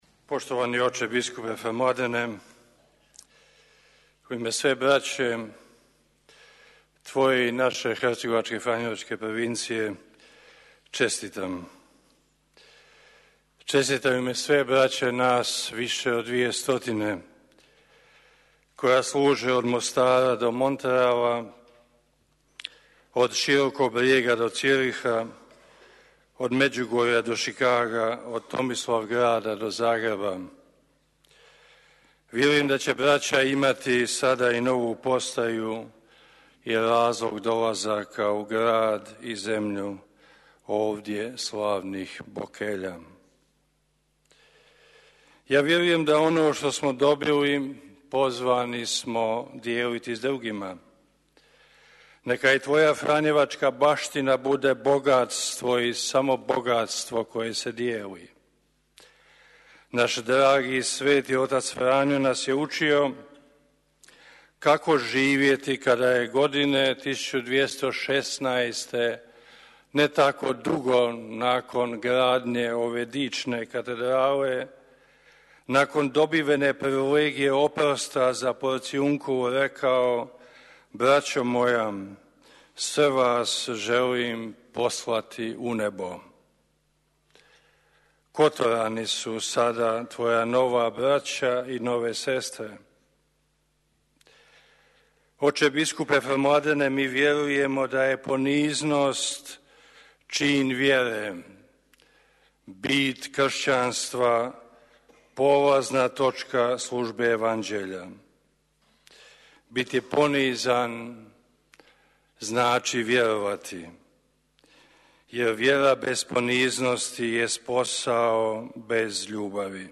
Govor
izrečen na završetku misnog slavlja prigodom biskupskog ređenja kotorskog biskupa mons. Mladena Vukšića, u subotu 23. studenoga 2024. godine, u kotorskoj katedrali Sv. Tripuna, prenosimo u cijelosti.